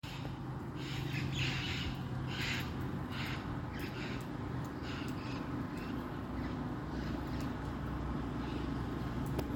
Maitaca-verde (Pionus maximiliani)
Nome em Inglês: Scaly-headed Parrot
Localidade ou área protegida: San Miguel, capital
Condição: Selvagem
Certeza: Gravado Vocal
Loro-maitaca.mp3